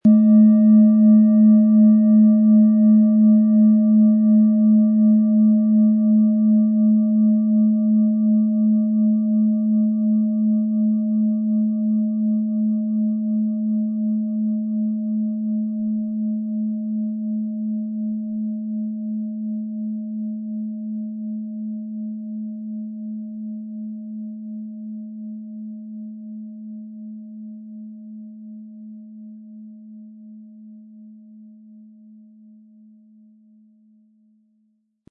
Planetenton 1
Es ist eine von Hand getriebene Klangschale, aus einer traditionellen Manufaktur.
Im Sound-Player - Jetzt reinhören hören Sie den Original-Ton dieser Schale.
Sanftes Anspielen mit dem gratis Klöppel zaubert aus Ihrer Schale berührende Klänge.
MaterialBronze